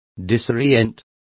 Complete with pronunciation of the translation of disoriented.